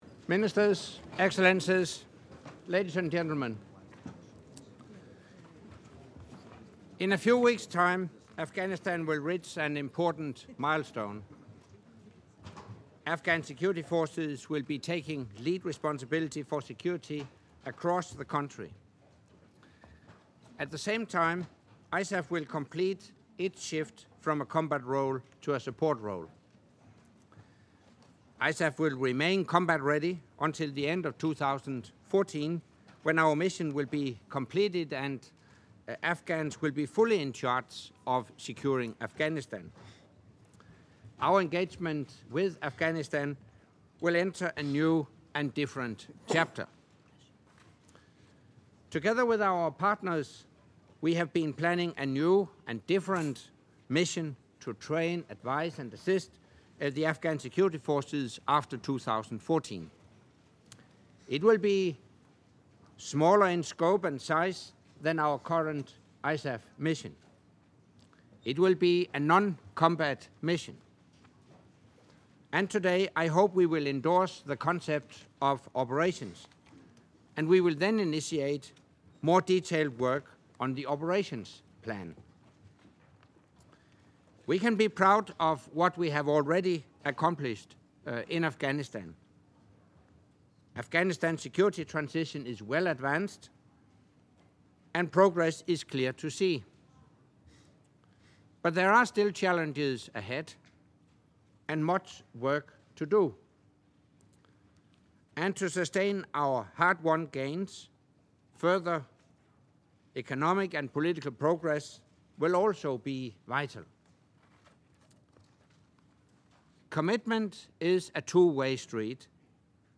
Opening remarks by NATO Secretary General Anders Fogh Rasmussen at the meeting of the NATO Defence Ministers with non-NATO ISAF contributing nations